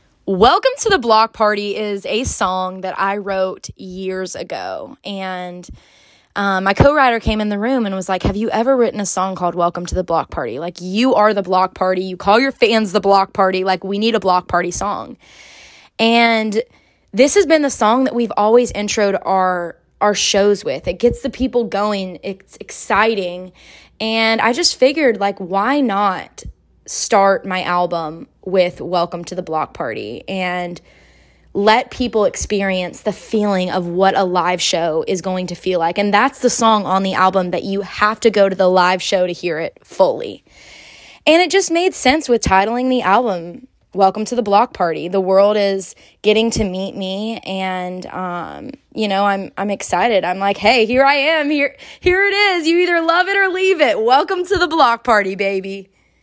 Audio / Priscilla Block explains why she put the intro track of a portion of “Welcome To The Block Party” on her album of the same name.